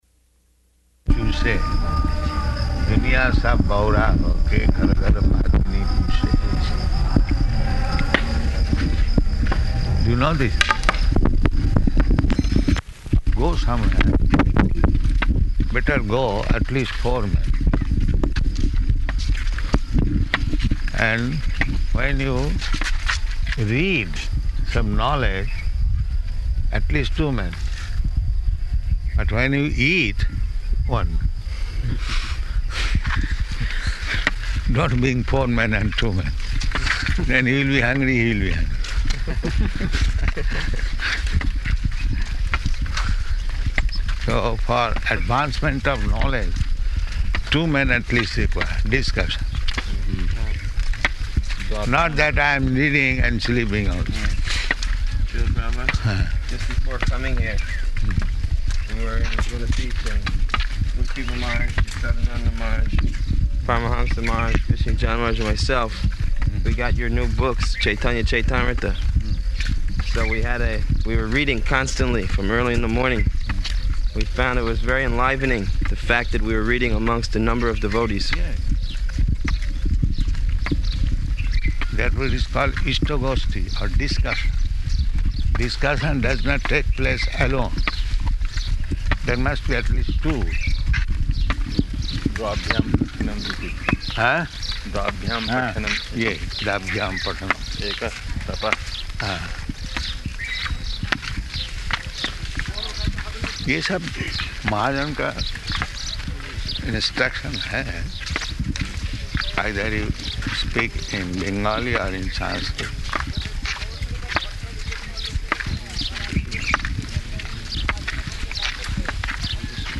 Morning Walk --:-- --:-- Type: Walk Dated: September 9th 1975 Location: Vṛndāvana Audio file: 750909MW.VRN.mp3 Prabhupāda: [Hindi] Do you know this?